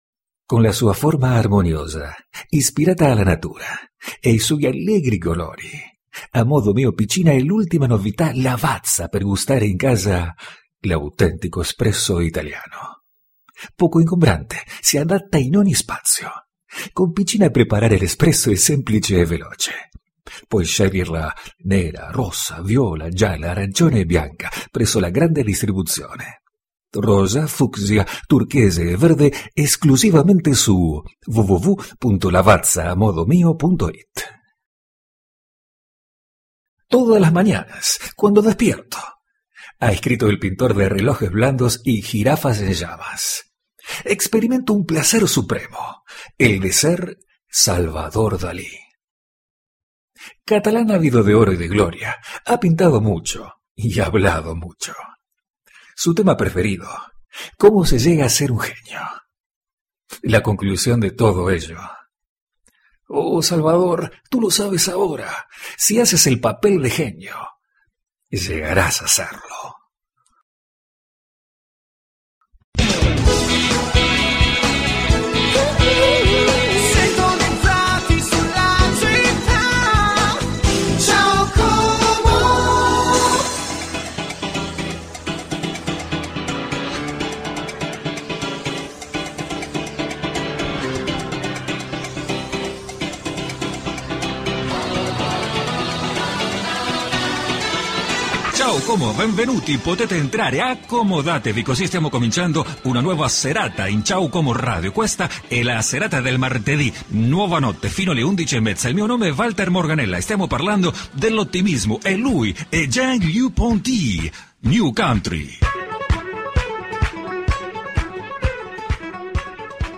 Voz càlida , cordial , elegante y ùnica.
Sprechprobe: Werbung (Muttersprache):
Voice warm, friendly, elegant and unique.